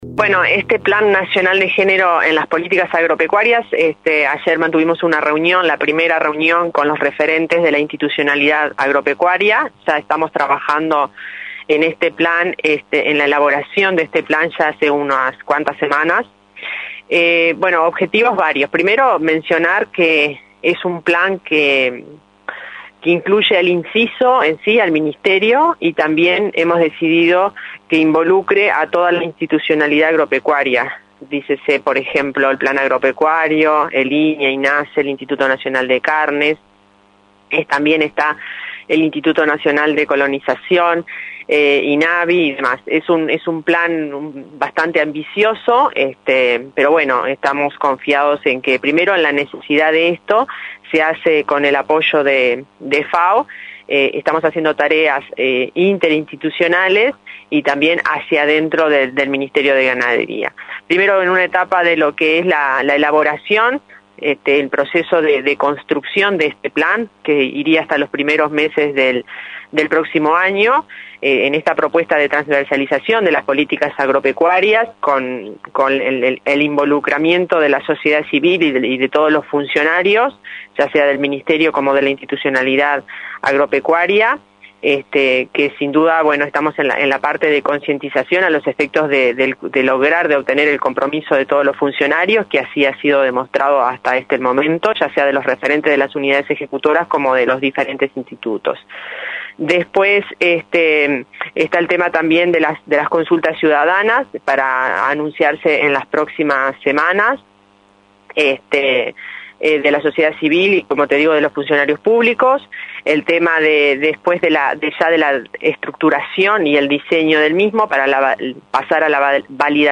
Maldonado, en entrevista con Comunicación Presidencial, describió que uno de los temas que mencionó fue el apoyo económico a políticas específicas para que las mujeres rurales o quienes se quieran vincular al sector desarrollen emprendimientos rurales existentes.
entrevista-a-fernanda-maldonado.mp3